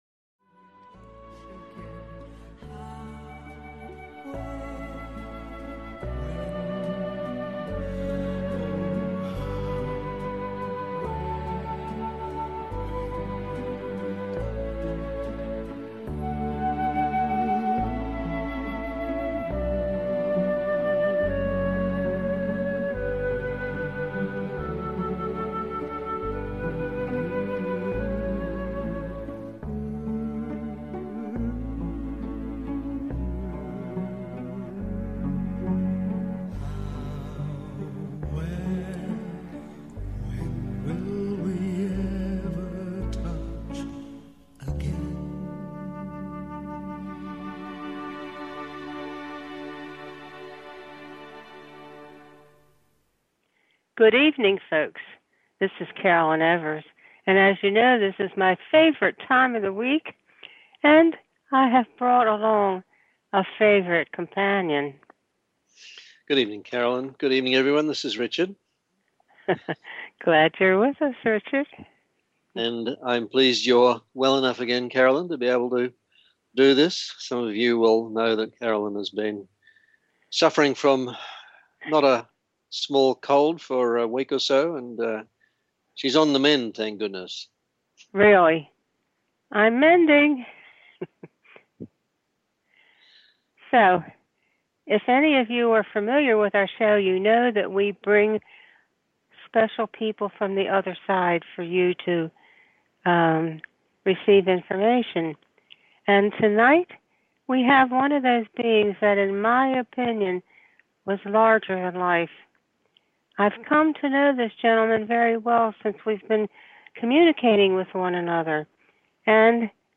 Talk Show Episode, Audio Podcast, The_Messenger and Courtesy of BBS Radio on , show guests , about , categorized as